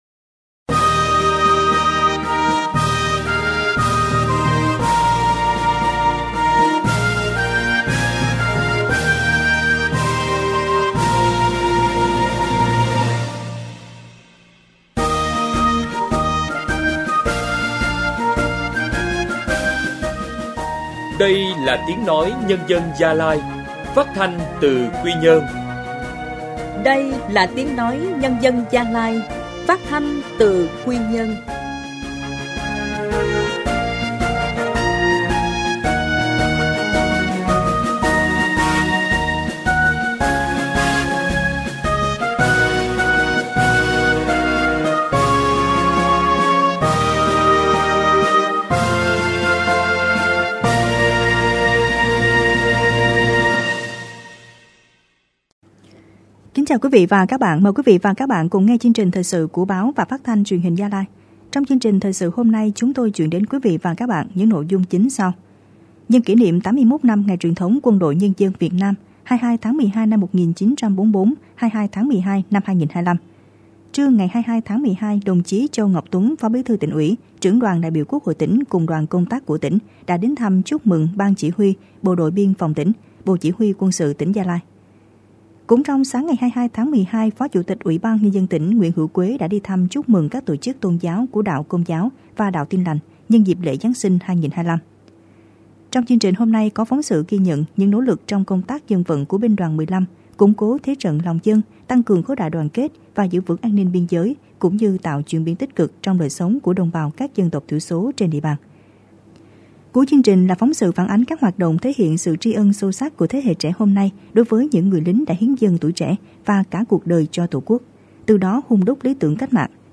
Thời sự phát thanh sáng